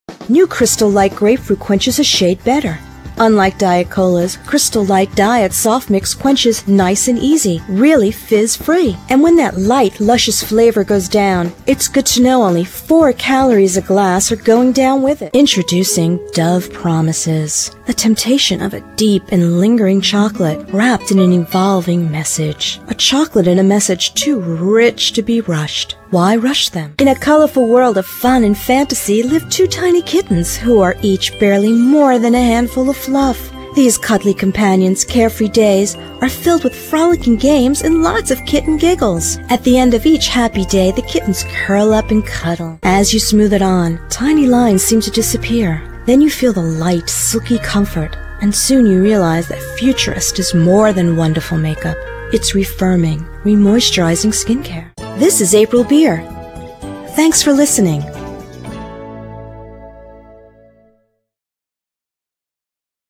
Female Voice-Over Talent
Commercial Demo:
NOTE: These files are high quality stereo audio files, therefore they are large in size.